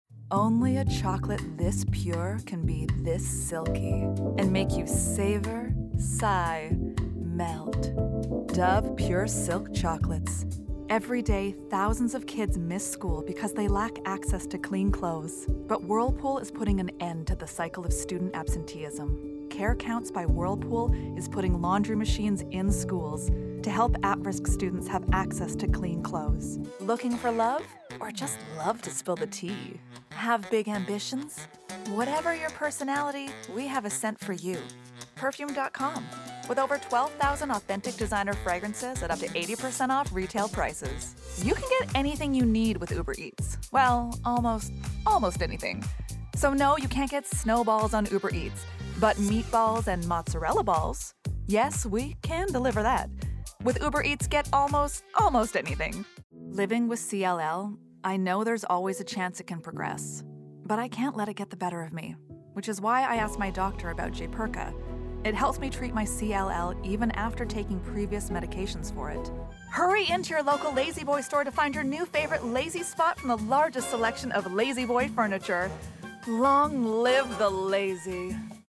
ACTOR